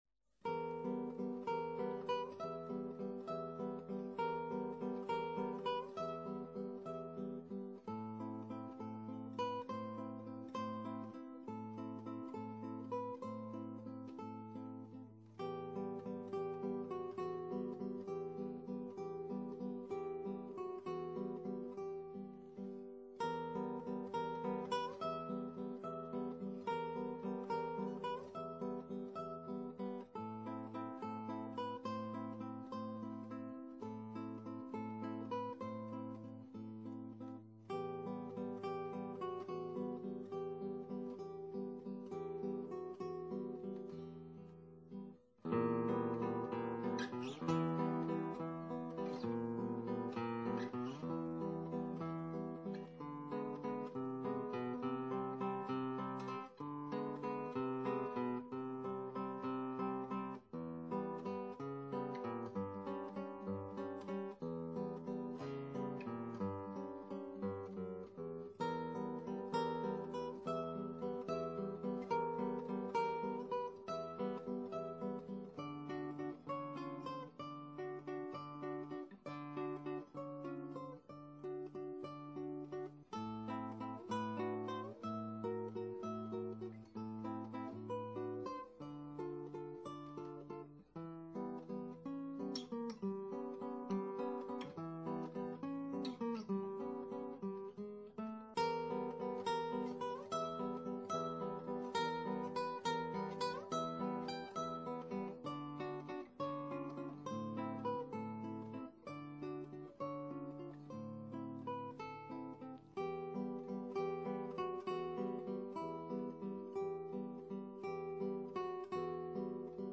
Original Works for Classical Guitar